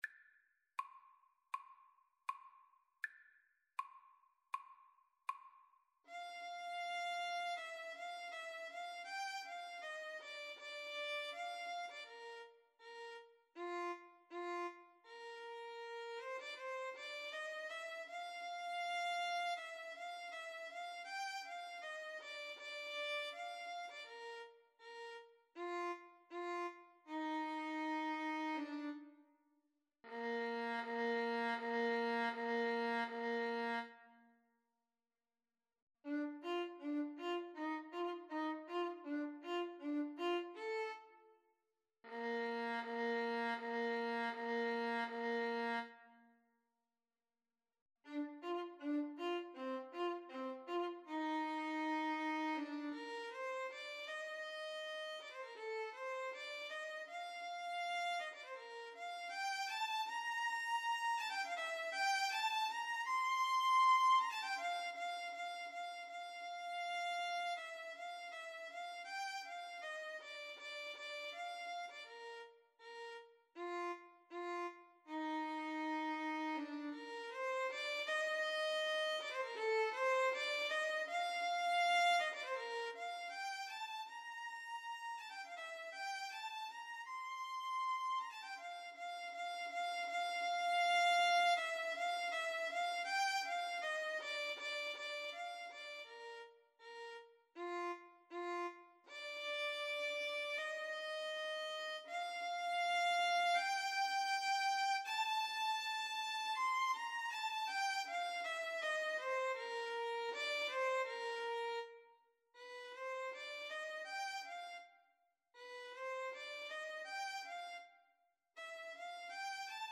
4/4 (View more 4/4 Music)
Andante
Classical (View more Classical Clarinet-Violin Duet Music)